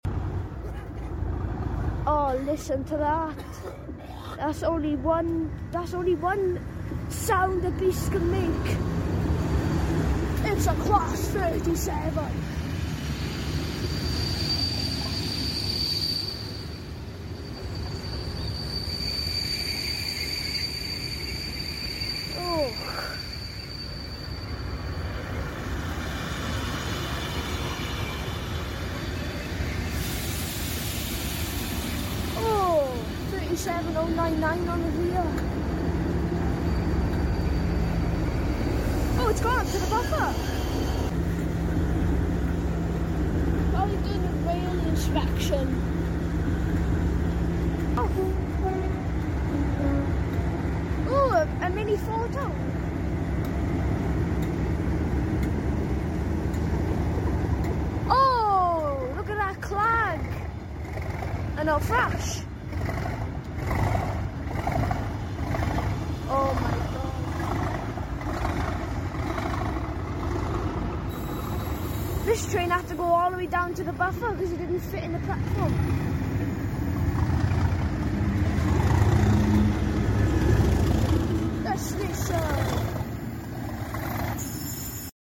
Class 37 At Maesteg Doing Sound Effects Free Download